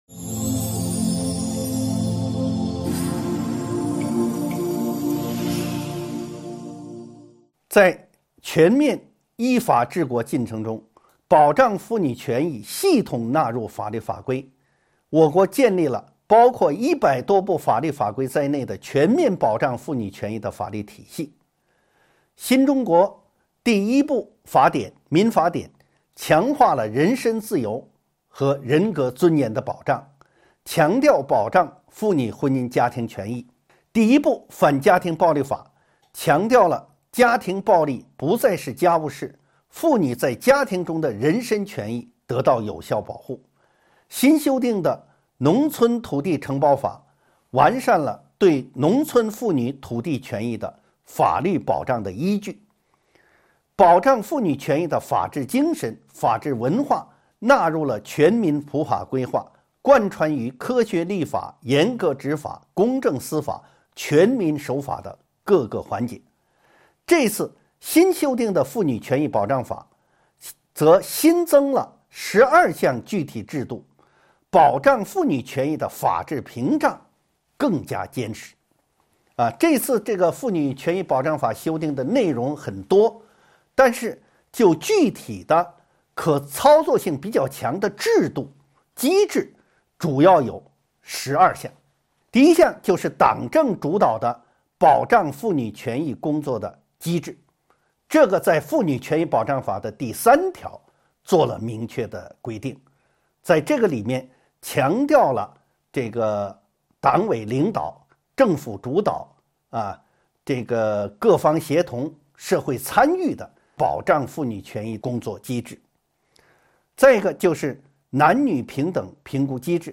音频微课：《中华人民共和国妇女权益保障法》5.新修订增加的新制度